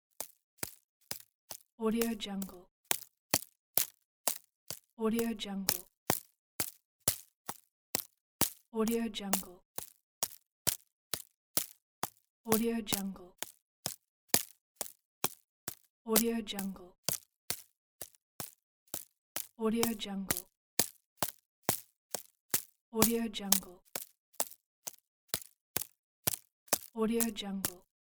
دانلود افکت صدای قدم زدن یک موجود
افکت صدای قدم زدن یک موجود، یک گزینه عالی برای پروژه هایی است که به صداهای طبیعت مانند راه رفتن و  قدم زدن نیاز دارند.
Sample rate 16-Bit Stereo, 44.1 kHz
Looped No